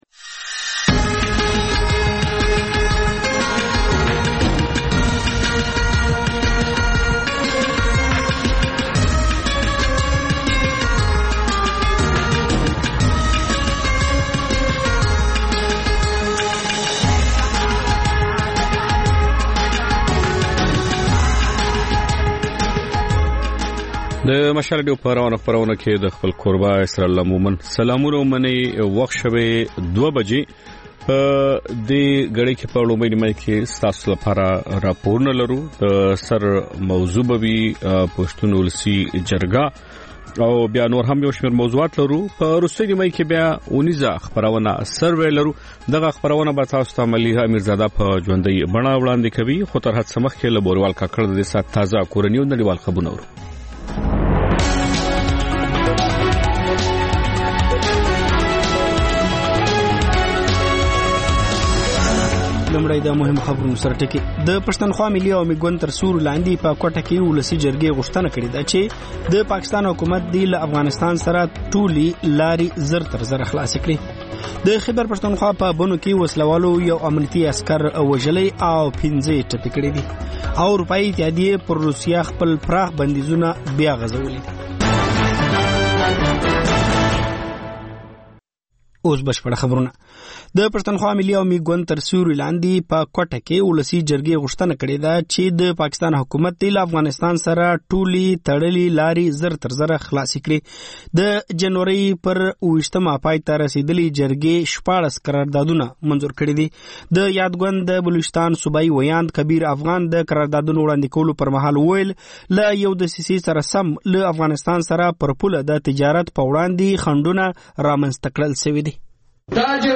د مشال راډیو دویمه ماسپښینۍ خپرونه. په دې خپرونه کې لومړی خبرونه او بیا ځانګړې خپرونې خپرېږي.